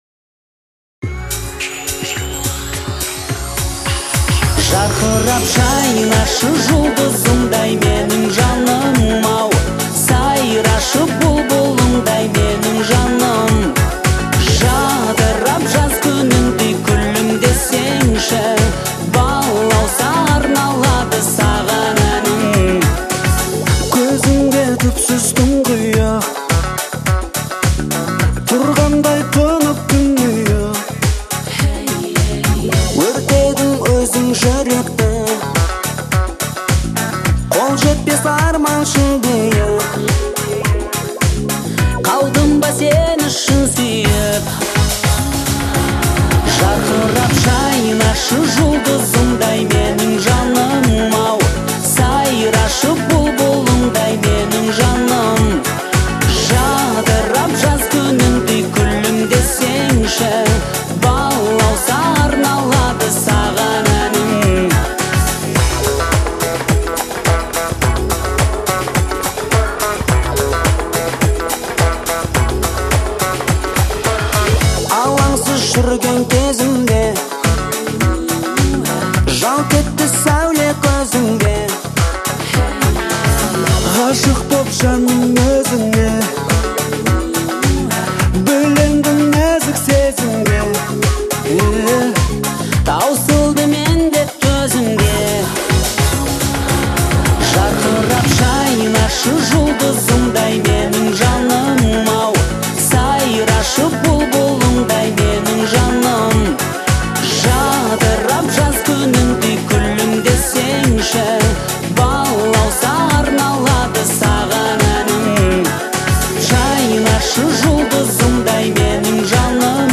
это яркий пример казахского поп-фольклора